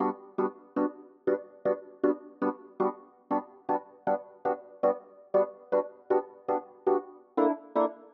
12 ElPiano PT2.wav